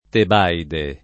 teb#ide] top. f. stor. (Eg.) — non sempre con T- maiusc. se usato come antonomasia di «luogo di ritiro» o «luogo di santa vita»: in somma è diventato quel castello una Tebaide [in S1mma H ddivent#to kUel kaSt$llo una teb#ide] (Manzoni); era diventata una tebaide di casti eremiti [$ra divent#ta una teb#ide di k#Sti erem&ti] (Bacchelli)